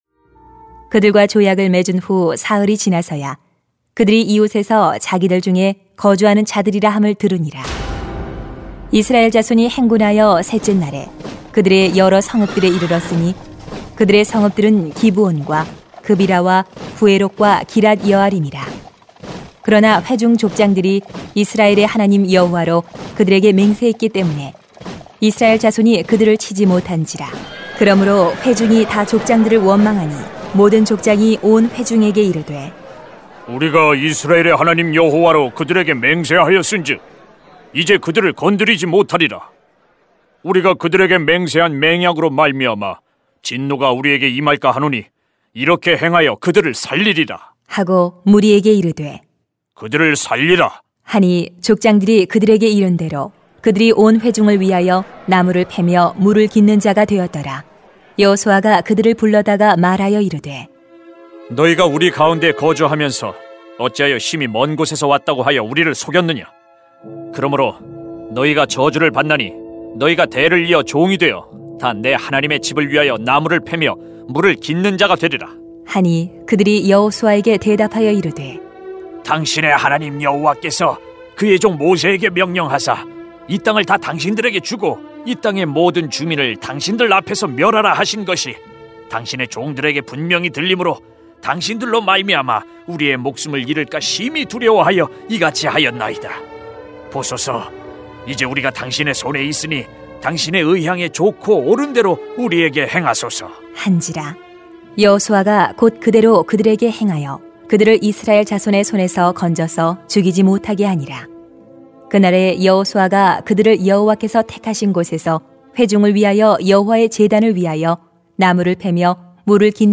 [수 9:16-27] 오늘까지 신실하게 하나되는 믿음 > 새벽기도회 | 전주제자교회